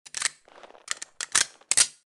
Звук перезарядки пистолета Беретта